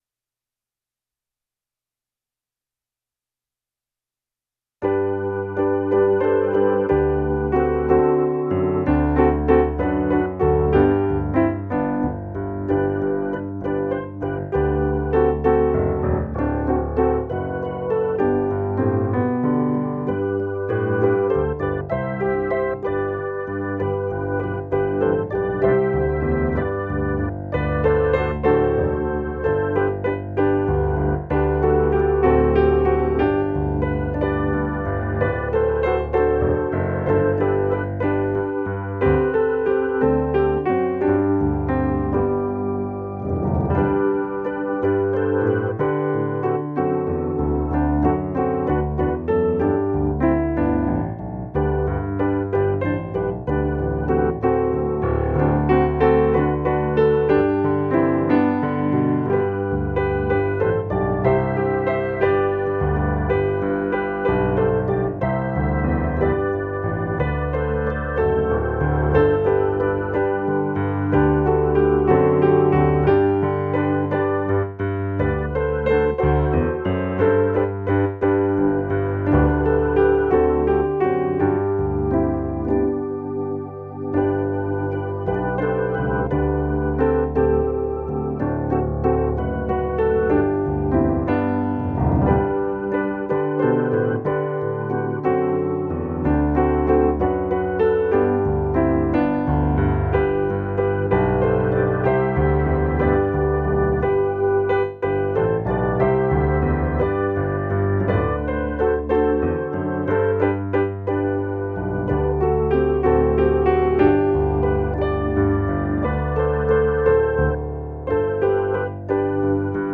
playback-hino-109.mp3